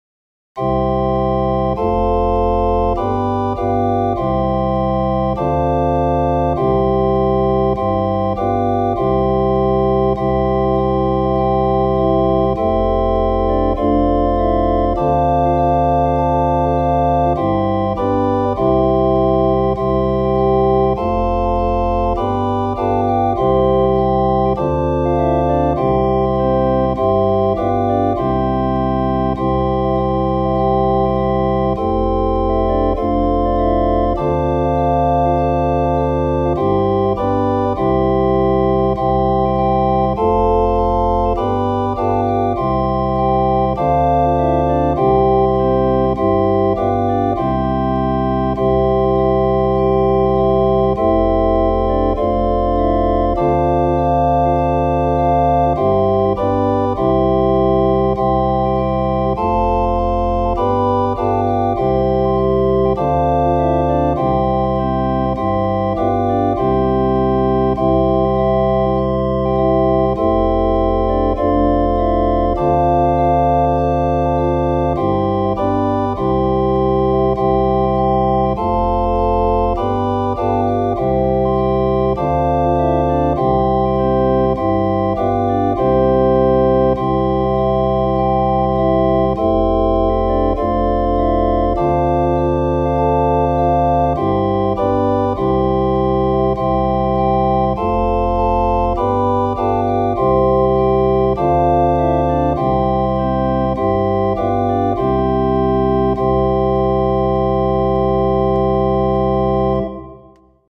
Marian hymn
I’ve down a fake organ backing on BIAB.